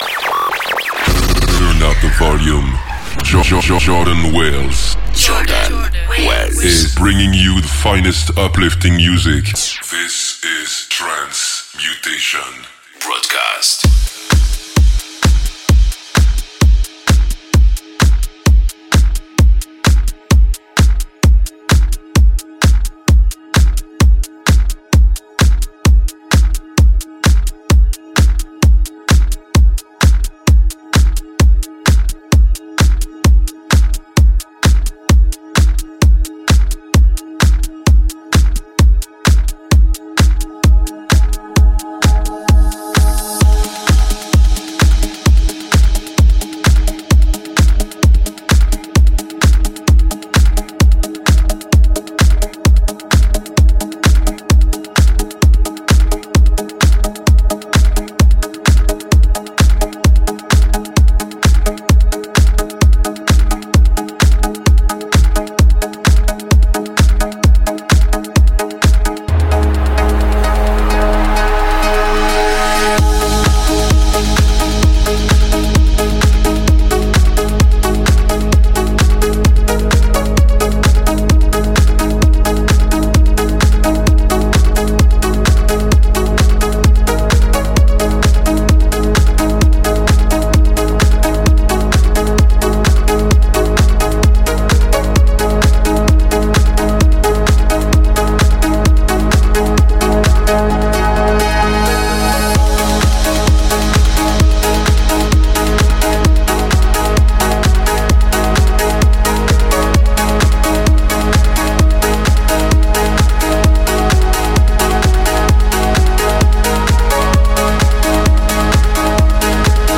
uplifting